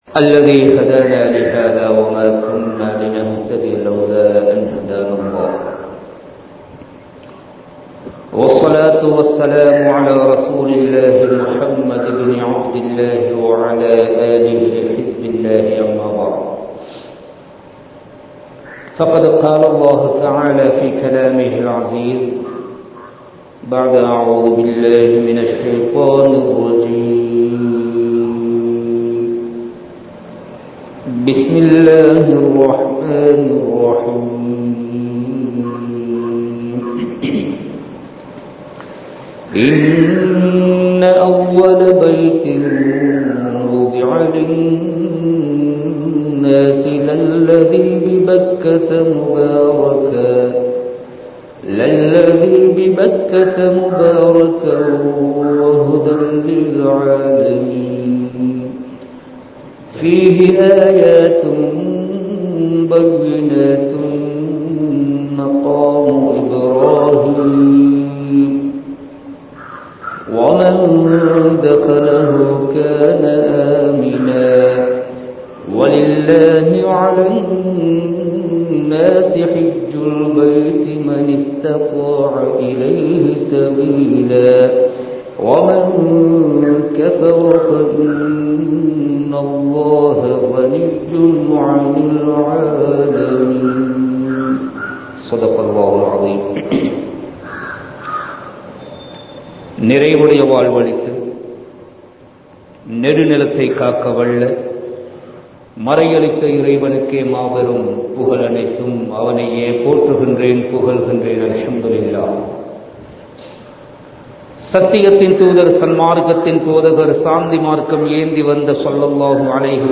ஹஜ்ஜின் சிறப்புகள் | Audio Bayans | All Ceylon Muslim Youth Community | Addalaichenai
Kandy, Kattukela Jumua Masjith